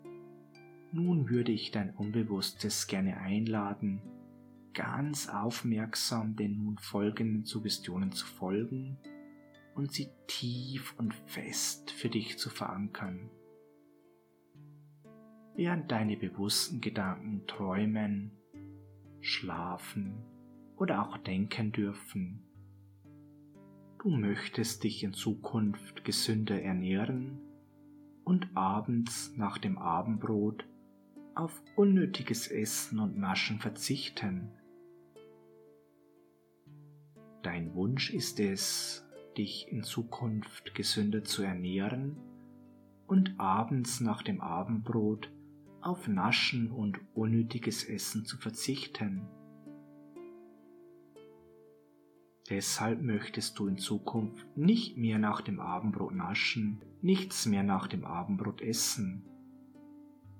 Erleben Sie eine geführte Hypnose und erlangen Sie die Kontrolle darüber, dass Sie abends nach dem Essen nicht mehr zu Naschzeug oder anderen Snacks greifen.